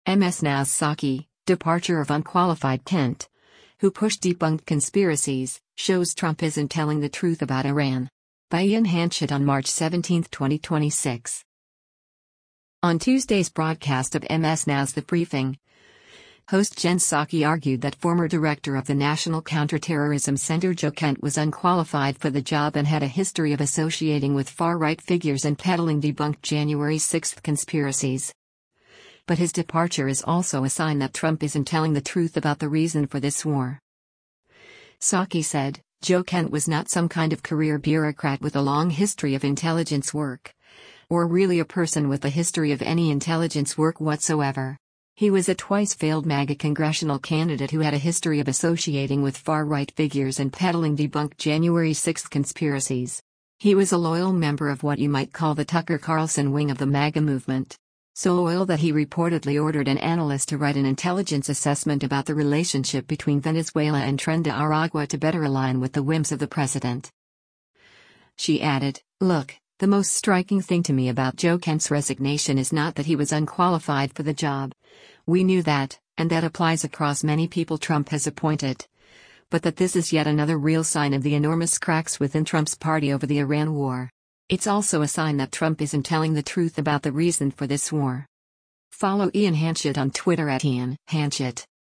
On Tuesday’s broadcast of MS NOW’s “The Briefing,” host Jen Psaki argued that former Director of the National Counterterrorism Center Joe Kent “was unqualified for the job” and “had a history of associating with far-right figures and peddling debunked January 6 conspiracies.” But his departure is “also a sign that Trump isn’t telling the truth about the reason for this war.”